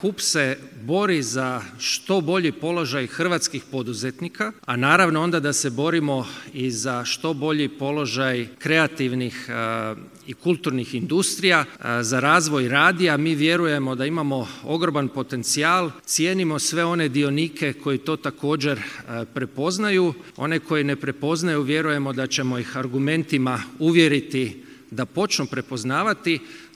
Tim povodom u petak je tradicionalno održan 5. Hrvatski radijski forum, na kojem su vodeći ljudi iz kulturne i kreativne industrije govorili upravo o Radiju i kreativnoj ekonomiji.